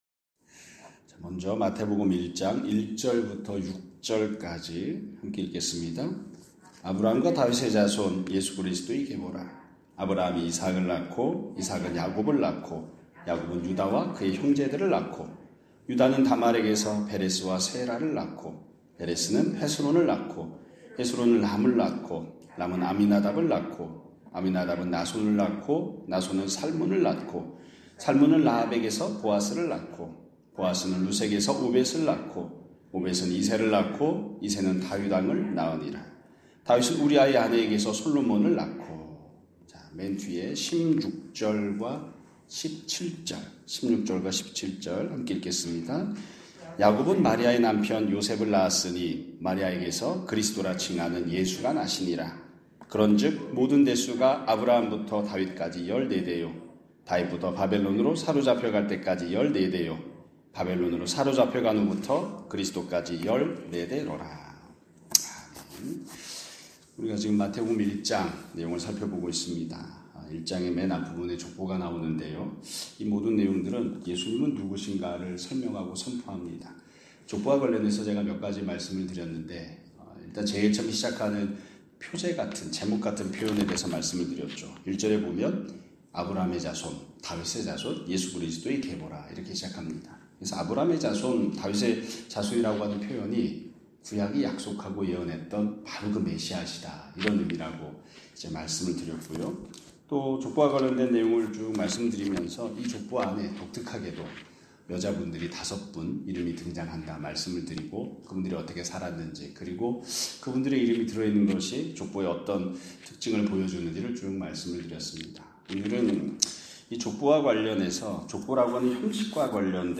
2025년 3월 21일(금요일) <아침예배> 설교입니다.